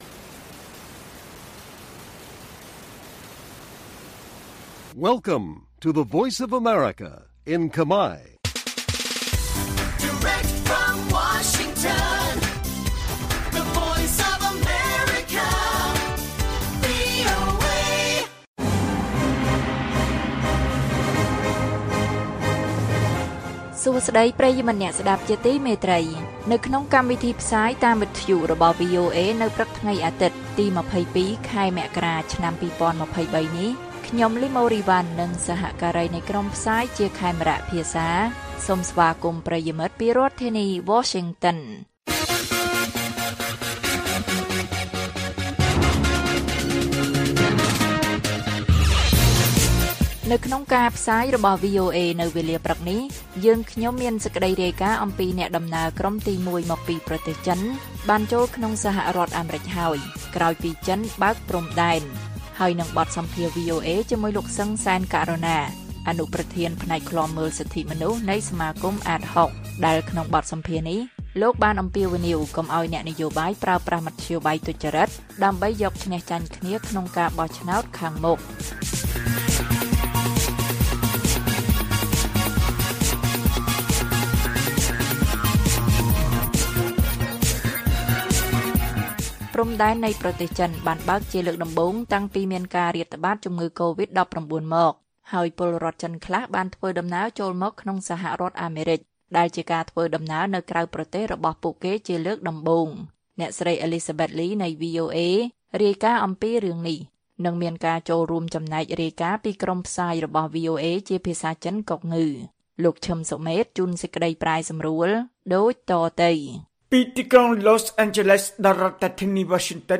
ព័ត៌មាននៅថ្ងៃនេះមានដូចជា អ្នកដំណើរក្រុមទីមួយមកពីប្រទេសចិនបានចូលក្នុងសហរដ្ឋអាមេរិកហើយ ក្រោយពីចិនបើកព្រំដែន។ បទសម្ភាសន៍ VOA៖ អ្នកជំនាញអំពាវនាវកុំឱ្យប្រើប្រាស់មធ្យោបាយទុច្ចរិតដើម្បីយកឈ្នះចាញ់គ្នាក្នុងការបោះឆ្នោតខាងមុខ៕